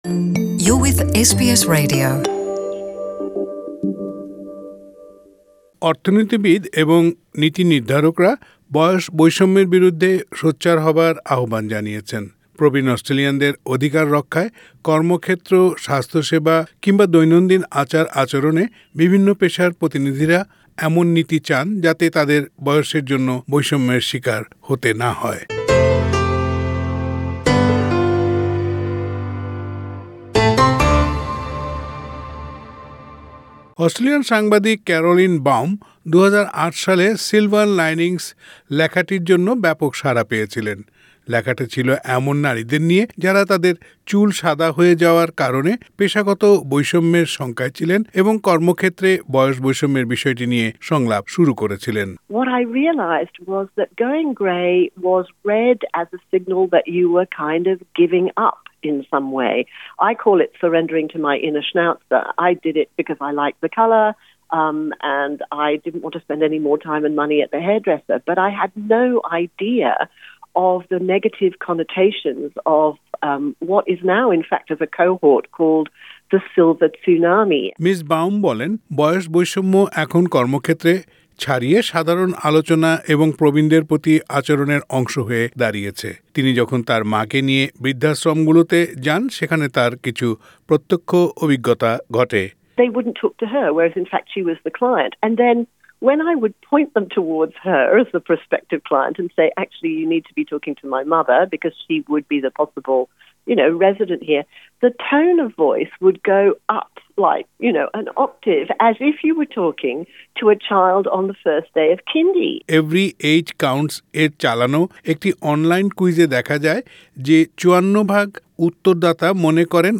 অর্থনীতিবিদ এবং নীতিনির্ধারকরা বয়স বৈষম্যের বিরুদ্ধে সোচ্চার হবার আহবান জানিয়েছেন। প্রবীণ অস্ট্রেলিয়ানদের অধিকার রক্ষায় কর্মক্ষেত্র, স্বাস্থসেবা কিংবা দৈনন্দিন আচার আচরণে নীতিনির্ধারকরা এমন নীতি চান যাতে তাদের বয়সের জন্য বৈষম্যের শিকার না হতে হয়। বয়স বৈষম্য নিয়ে এসবিএসের প্রতিবেদনটি শুনতে ওপরের লিংকে ক্লিক করুন।